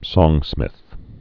(sôngsmĭth, sŏng-)